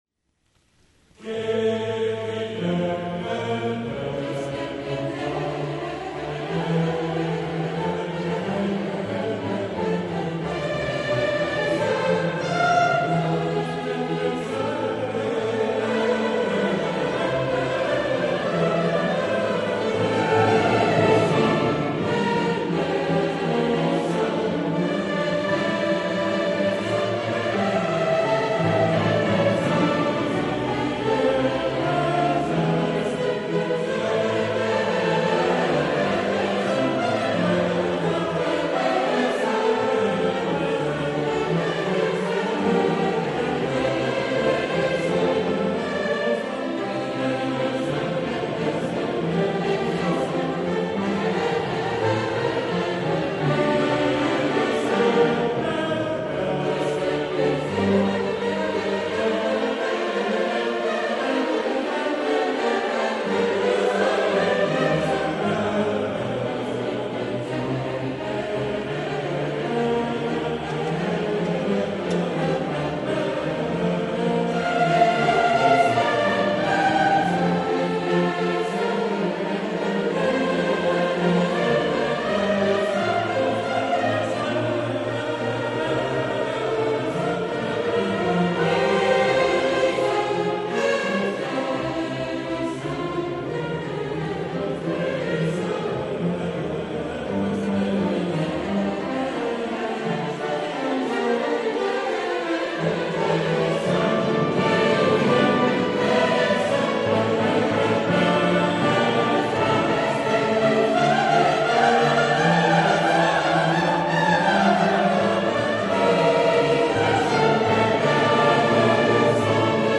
le choeur La Lyriade
l'orchestre l'Association Symphonique de Paris
Concert à Paris